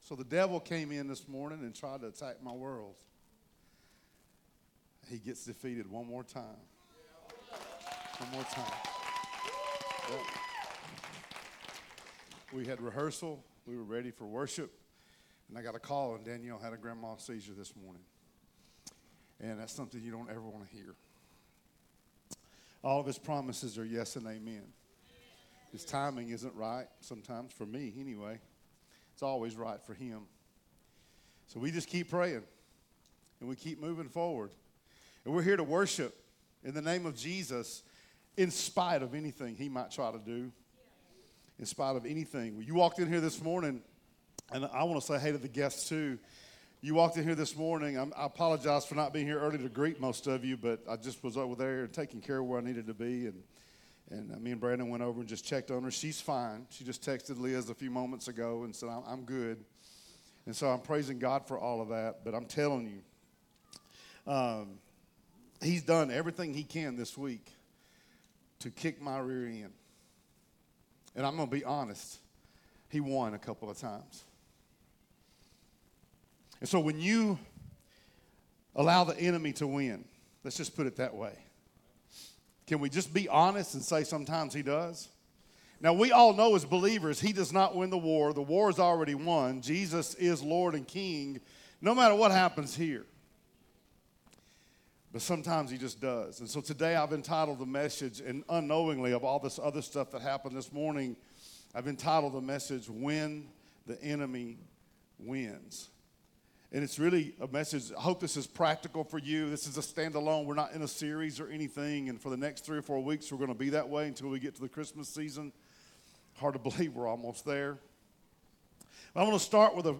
Sermons | Discovery Church